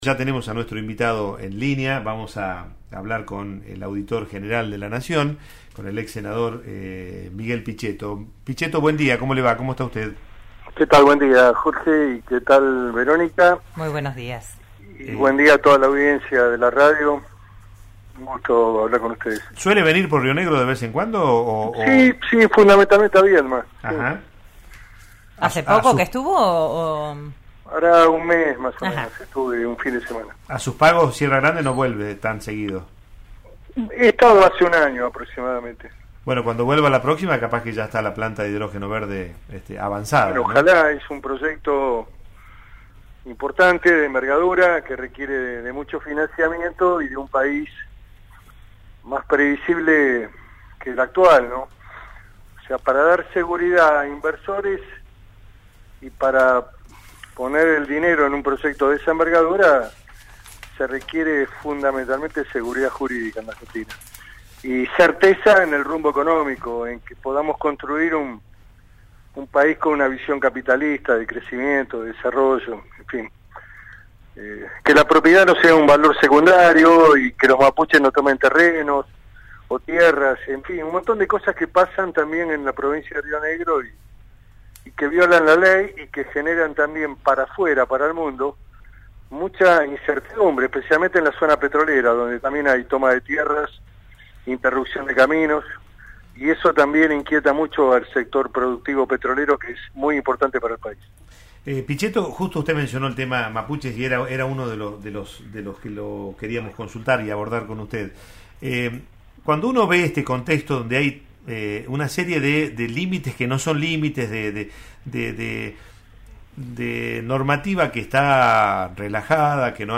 El actual auditor general de la Nación dialogó esta mañana con “Digan lo que Digan”, en RN RADIO, repasando la actualidad política nacional y regional.